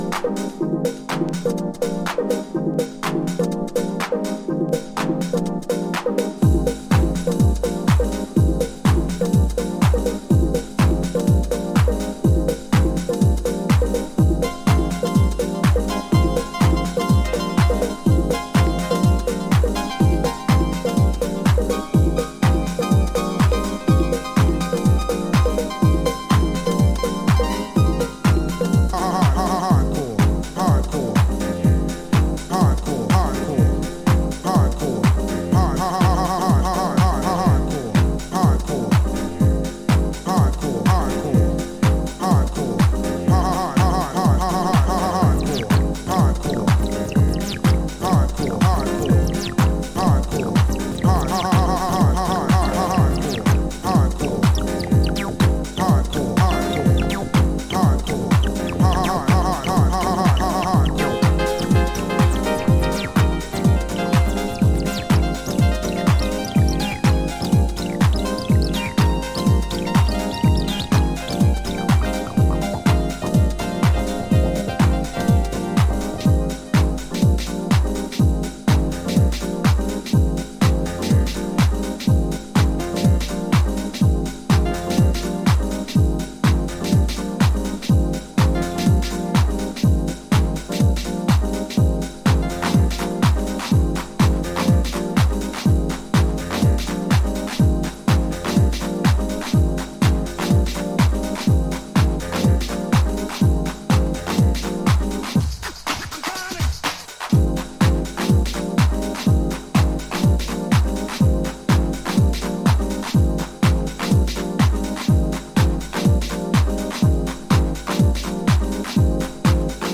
A crossover anthem